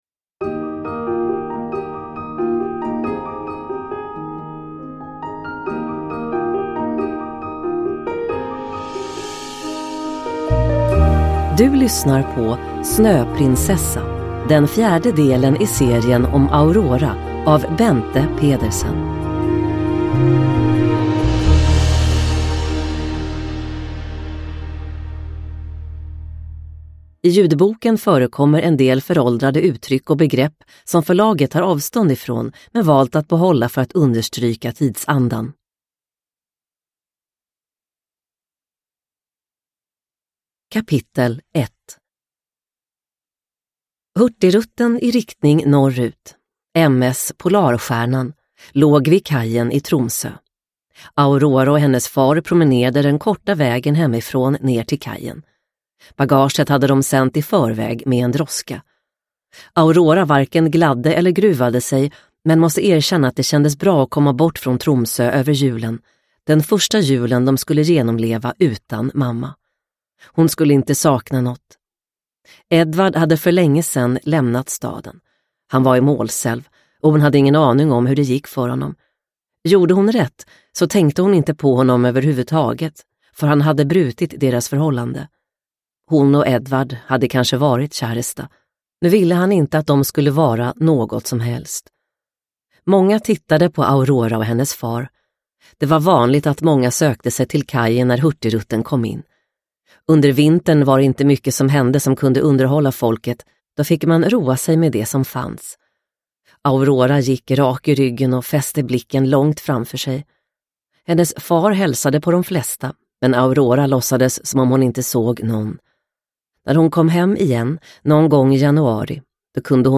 Snöprinsessa – Ljudbok – Laddas ner